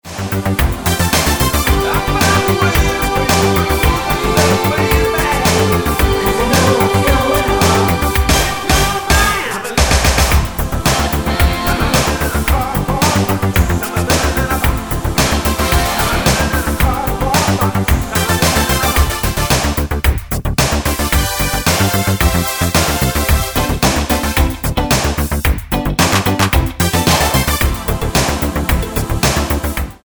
Tonart:Dm mit Chor
Die besten Playbacks Instrumentals und Karaoke Versionen .